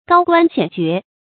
高官顯爵 注音： ㄍㄠ ㄍㄨㄢ ㄒㄧㄢˇ ㄐㄩㄝˊ 讀音讀法： 意思解釋： 顯：顯要，顯赫；爵：爵位，官爵。